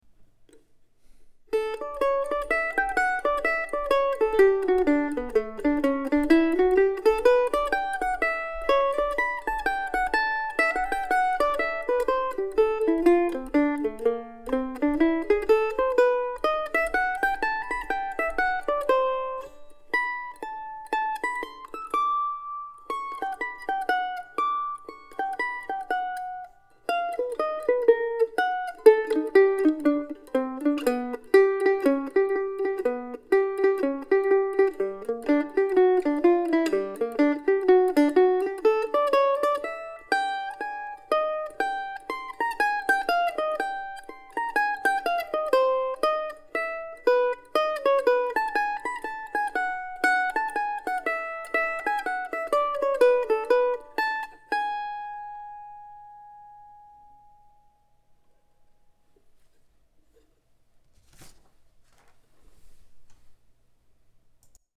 For anyone keeping score I can say that today's recording, and pretty much everything since "Snow Crow Shadow" in mid-January, was recorded using my recently acquired Lafferty LA-5 mandolin.